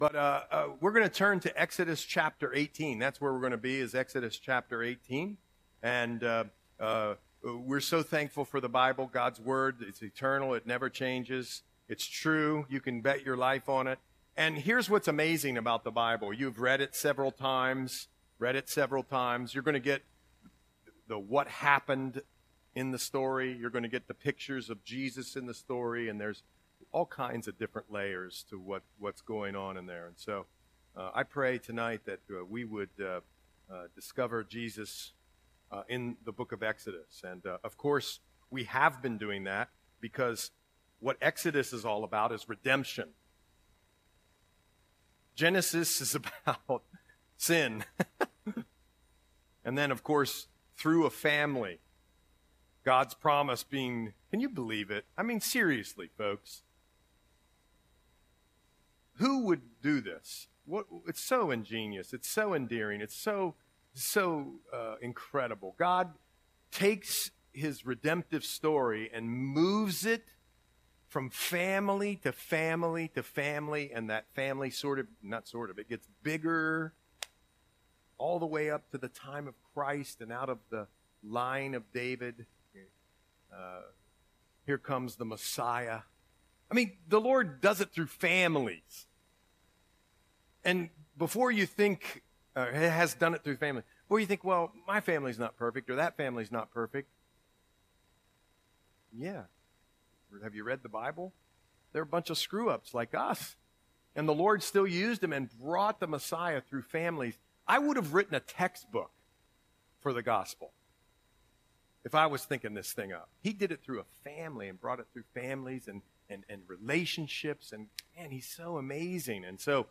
Audio Sermon - February 19, 2025